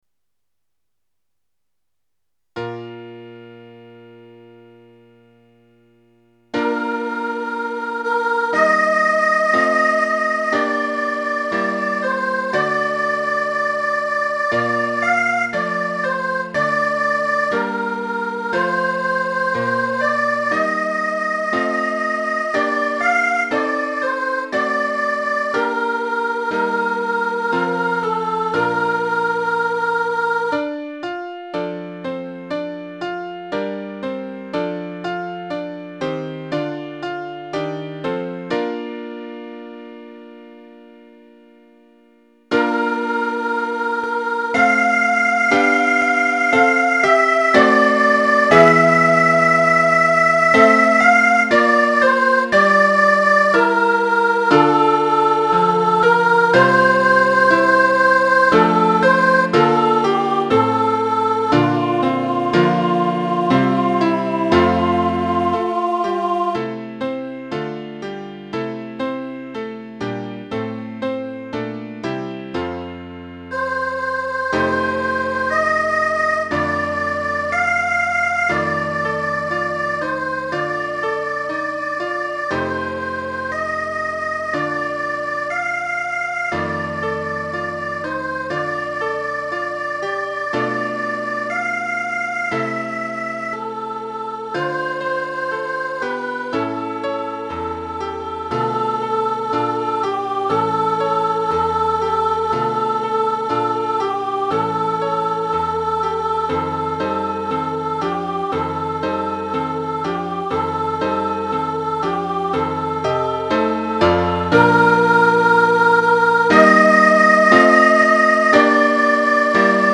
Ecoutez, en exclusivité, l'intégralité de la comédie musicale mise en scène par la classe de CP promo 06-07
Musique
Les chansons au format MP3 ne sont que des enregistrements de faibles qualités.
requiem.mp3